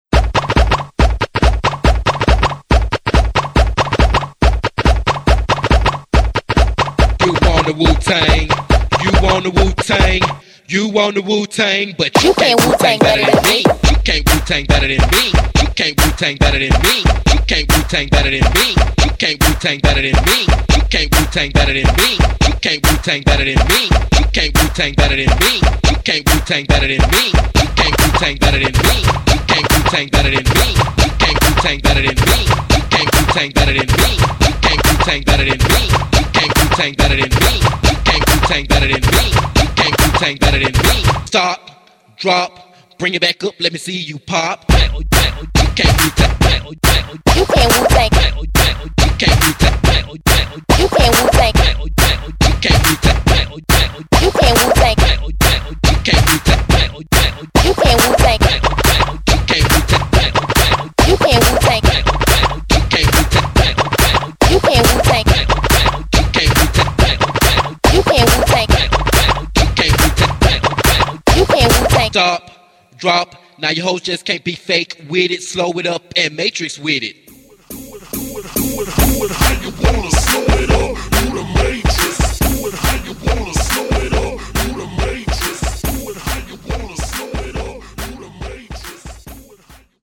Alabama's interpretation of Baltimore Club music